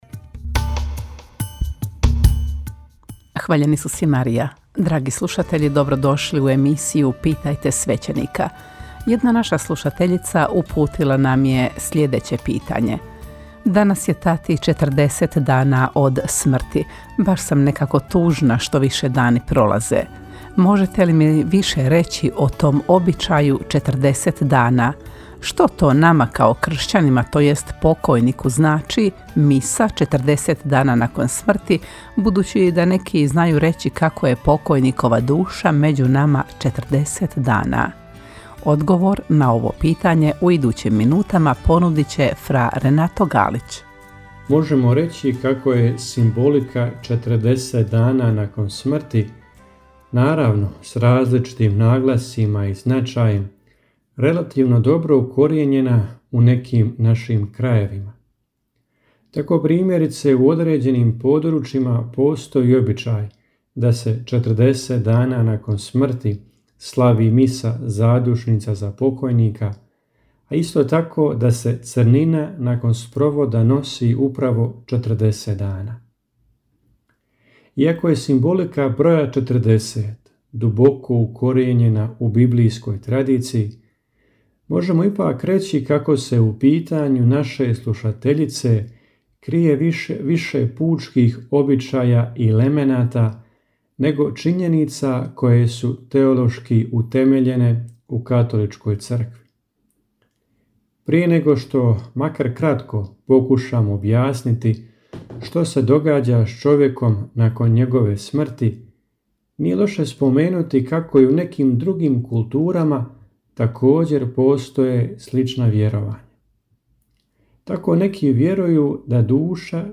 U njoj na pitanja slušatelja odgovaraju svećenici, suradnici Radiopostaje Mir Međugorje.